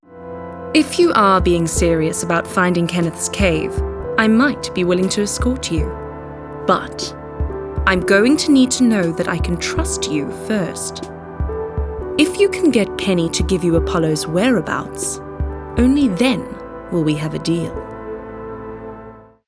Game narration
My neutral South African, British and American accents lend themselves to easy listening.